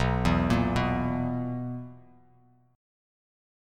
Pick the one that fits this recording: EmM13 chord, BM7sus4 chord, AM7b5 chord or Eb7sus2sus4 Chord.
BM7sus4 chord